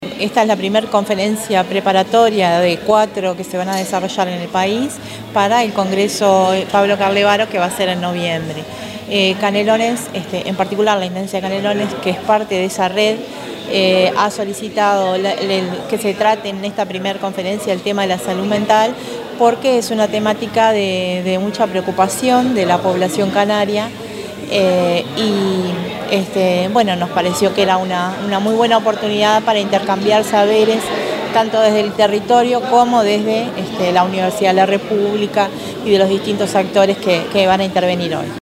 Se realizó la primera conferencia preparatoria Salud mental y abordajes comunitarios, organizada por la Universidad de la República y Apex y coorganizada por la Intendencia de Canelones a través de la Dirección de Salud, entre otras instituciones.